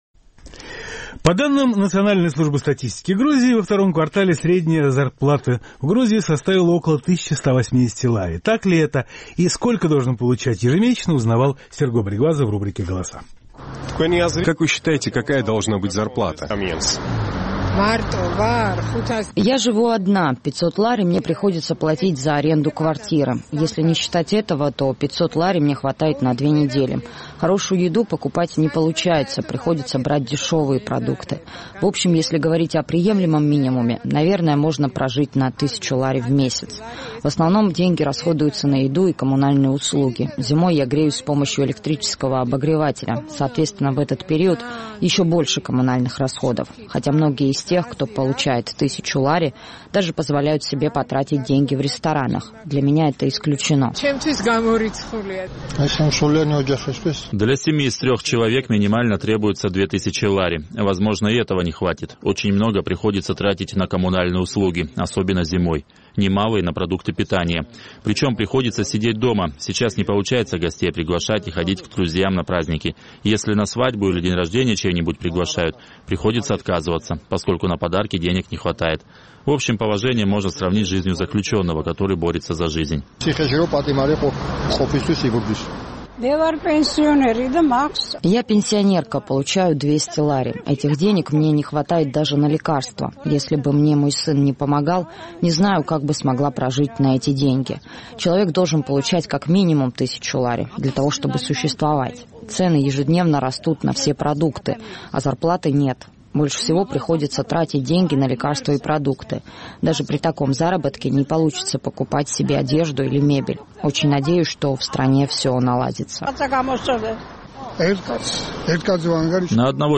Согласно данным Национальной службы статистики, во втором квартале текущего года среднемесячная номинальная заработная плата составляет около 1180 лари. Наш корреспондент поинтересовался, есть ли у жителей Тбилиси такой заработок и какой оклад, по их мнению, должен получать человек ежемесячно.